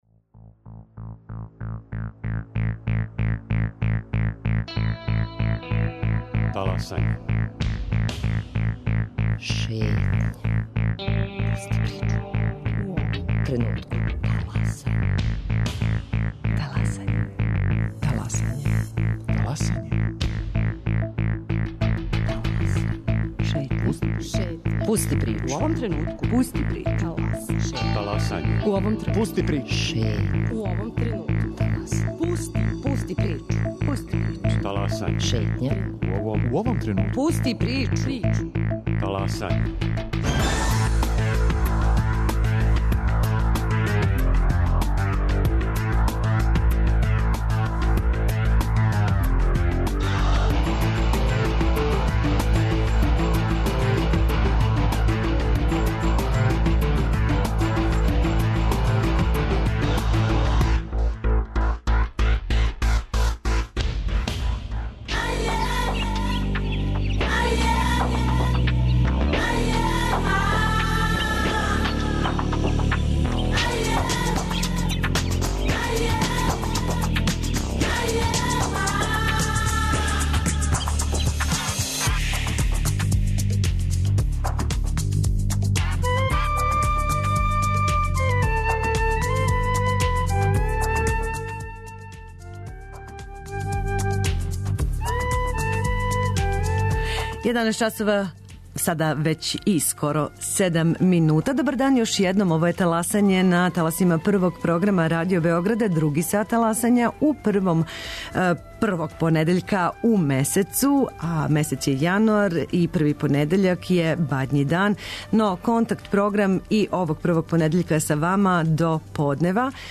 Први контакт-програм у Новој години!